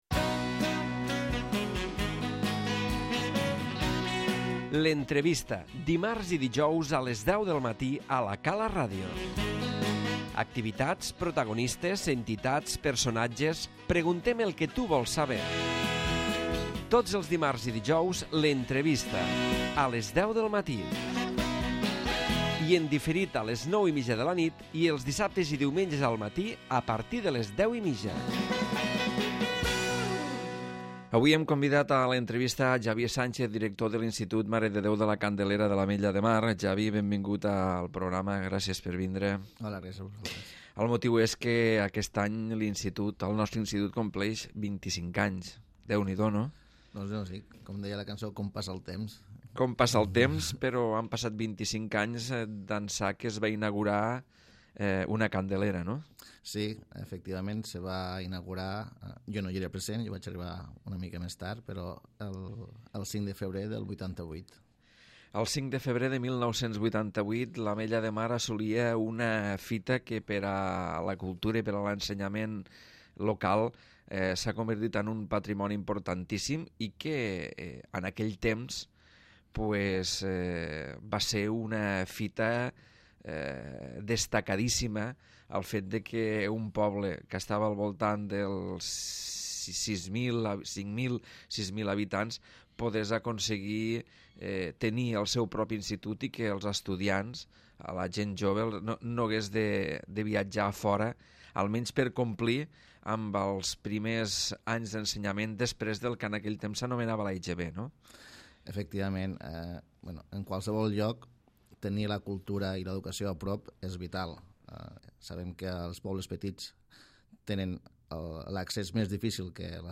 L'Entrevista